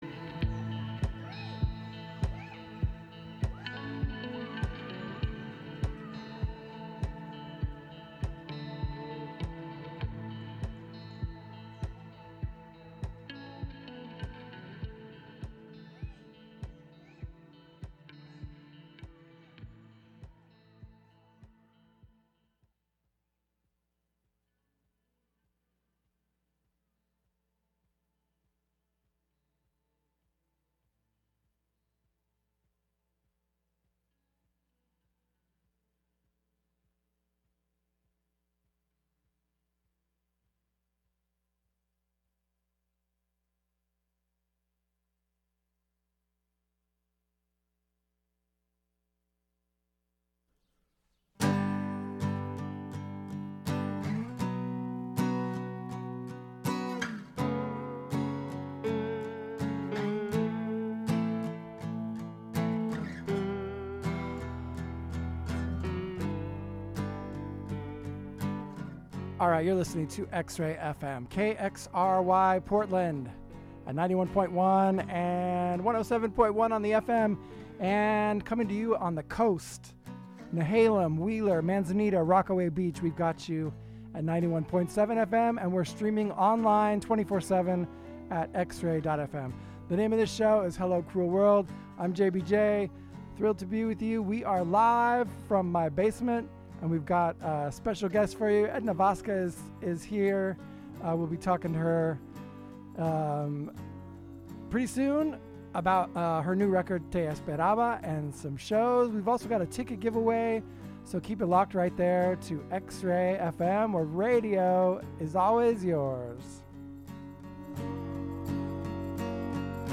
Hello Cruel World brings the worlds of singer/songwriters and acoustic music to you every Thursday from 3-4pm with conversations and in-studio performances as often as possible.